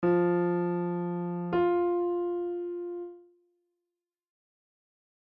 On the piano, play the following:
F - then up an octave - play the next F